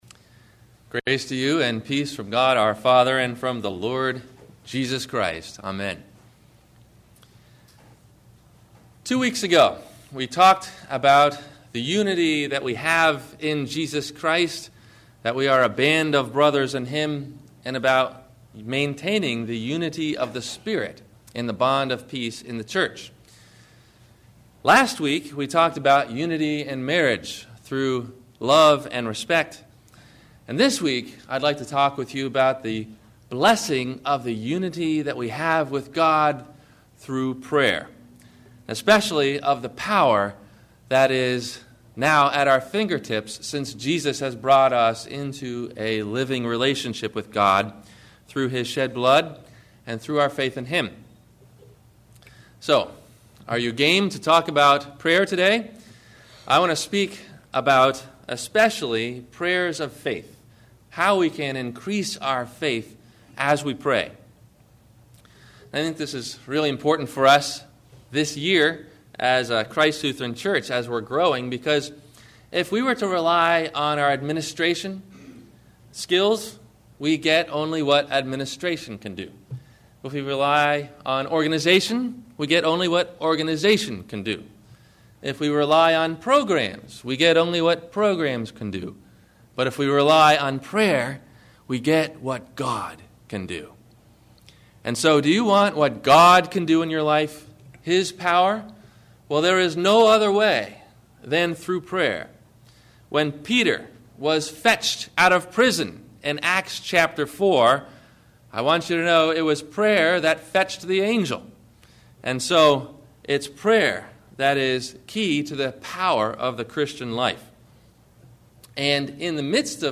The Prayer of Faith - Sermon - January 20 2008 - Christ Lutheran Cape Canaveral